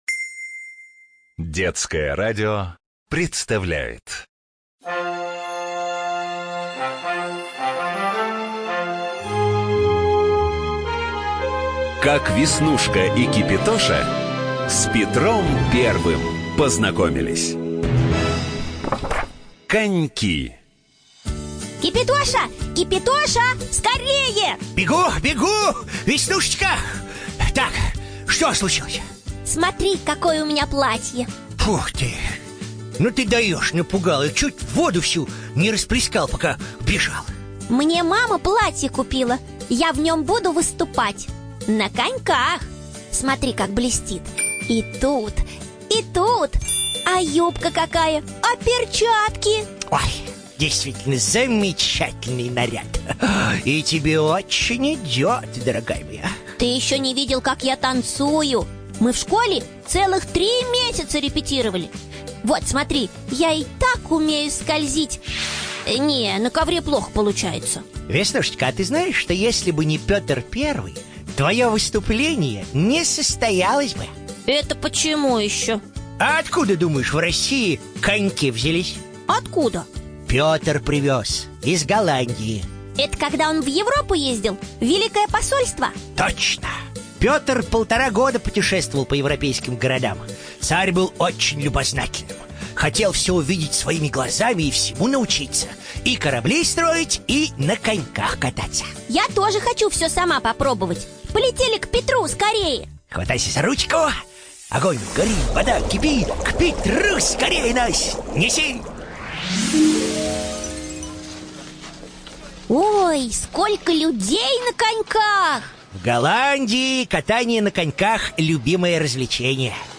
НазваниеКак Веснушка и Кипятоша с Петром Первым познакомились. Цикл радиопередач
ЧитаетДетское радио
Студия звукозаписиДетское радио